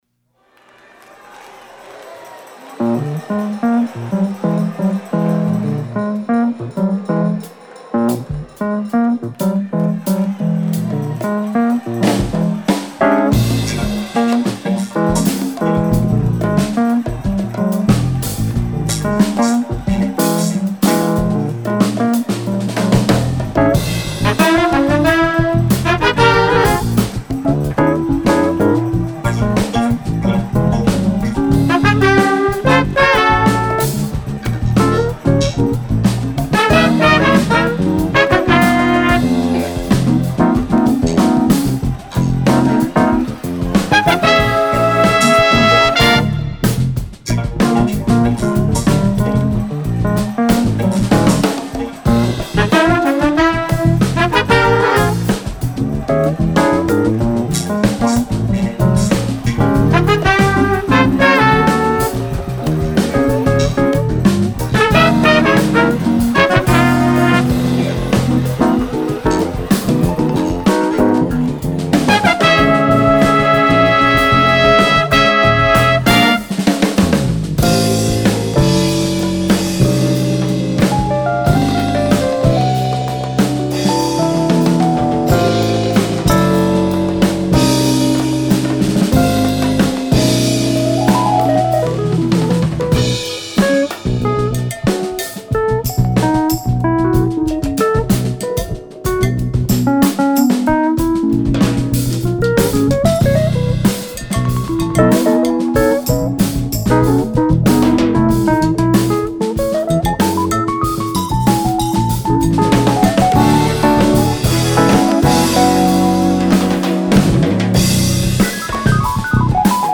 Electric Piano, Synthesizers, Sound Effects